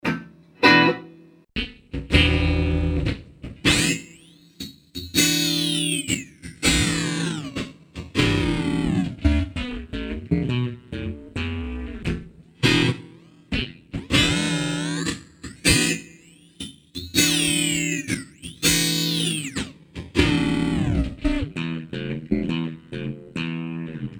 Guitar effect processor (2000)
guitar phaser